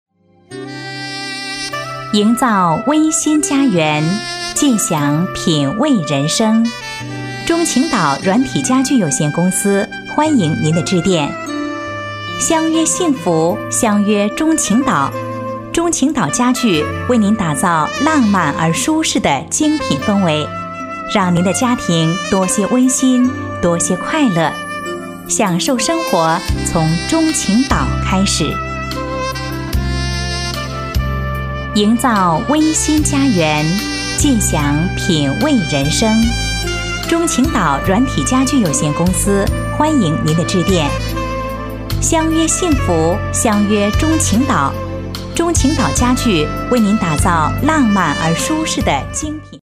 女声配音
彩铃女国46